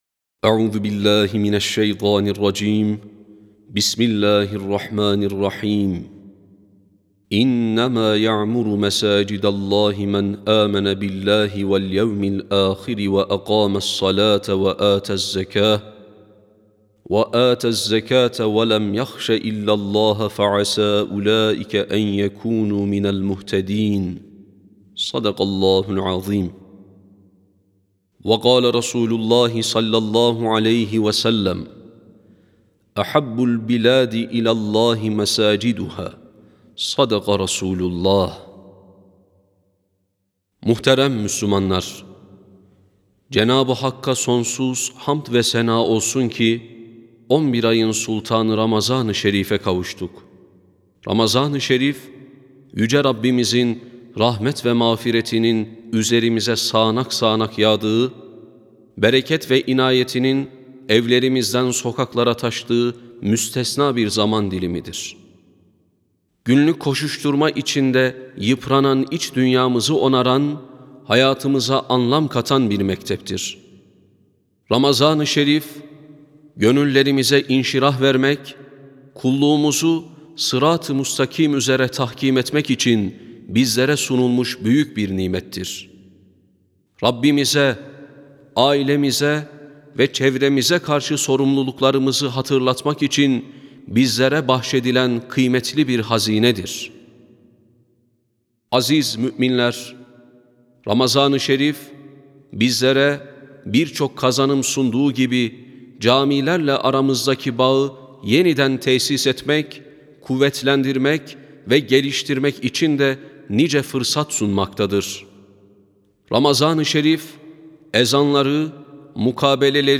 20.02.2026 Cuma Hutbesi: Ramazan, Cami ve Hayat (Sesli Hutbe, Türkçe, İngilizce, Rusça, Arapça, İtalyanca, Fransızca, İspanyolca, Almanca)